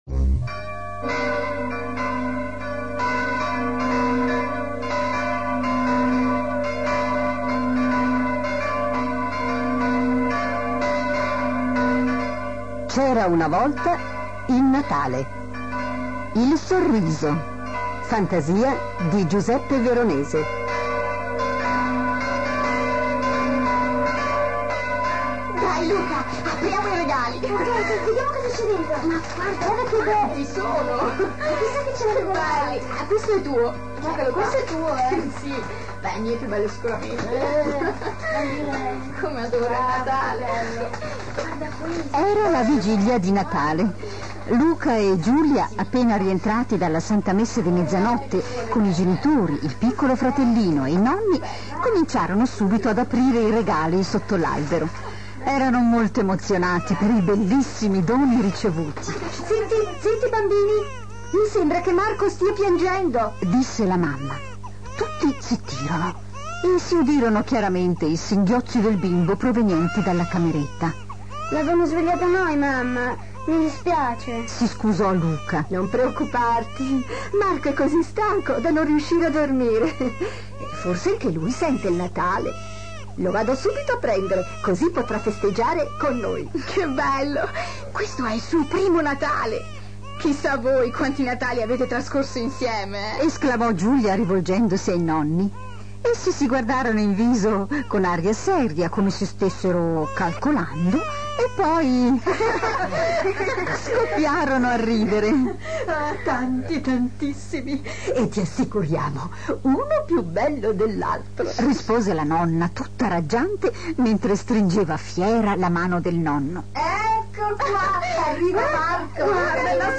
Potete ascoltare il racconto in rete, cliccando su questo testo scorrevole ... Ringrazio la Radio della Svizzera Italiana per la bellissima interpretazione!